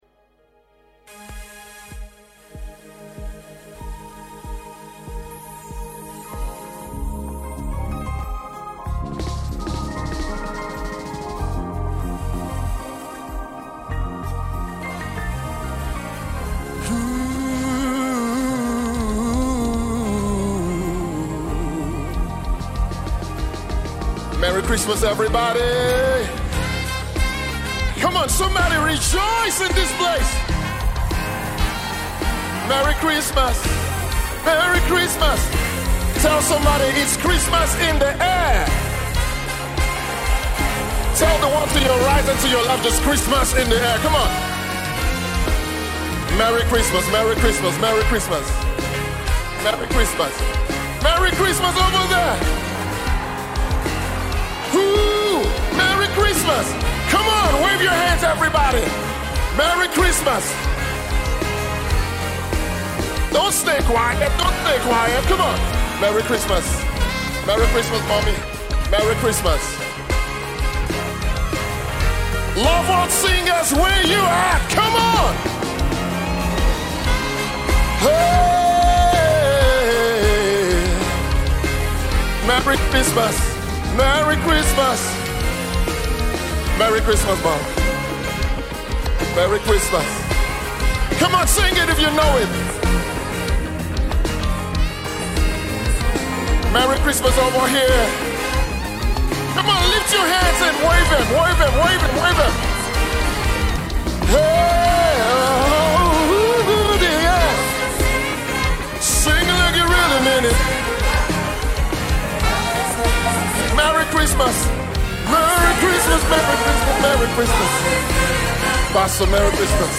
MORE CHRISTMAS SONGS/CAROLS